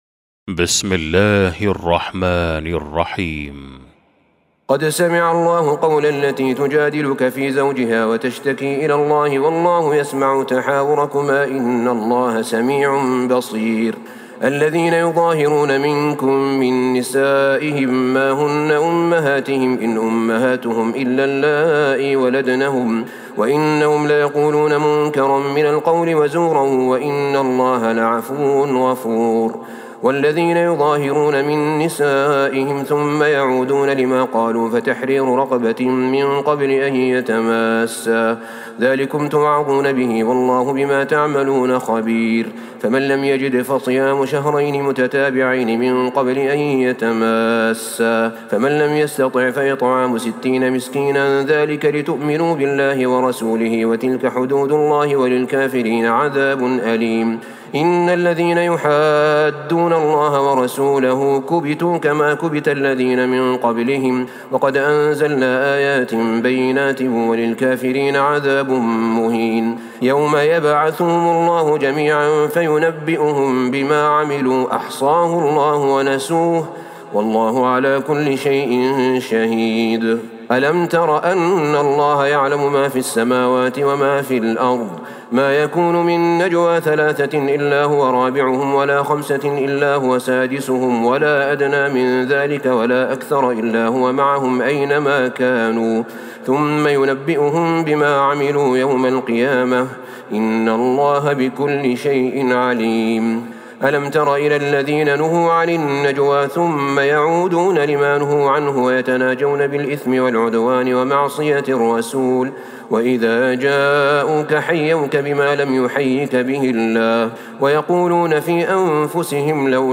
سورة المجادلة Surat Al-Mujadilah > مصحف تراويح الحرم النبوي عام 1443هـ > المصحف - تلاوات الحرمين